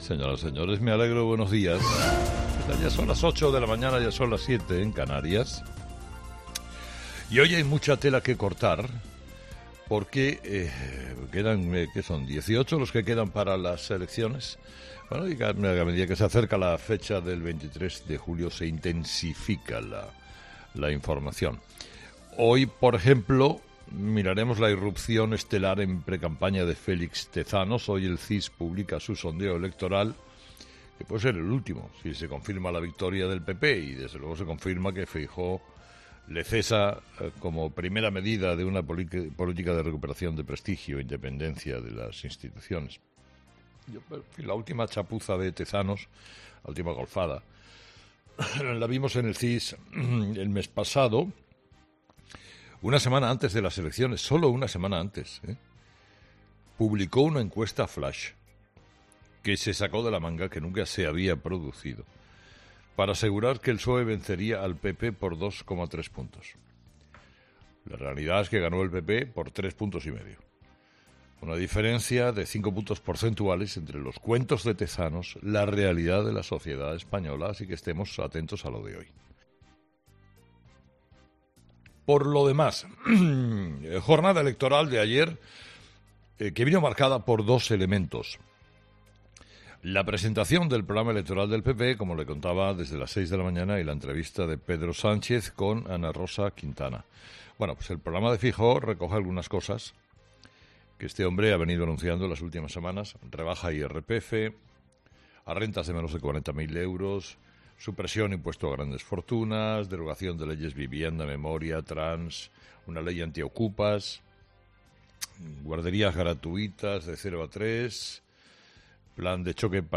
Escucha el monólogo de Carlos Herrera y su consejo a Feijóo para preparar el debate con Sánchez